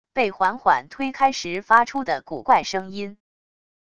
被缓缓推开时发出的古怪声音wav音频